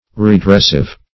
Redressive \Re*dress"ive\ (-?v), a. Tending to redress.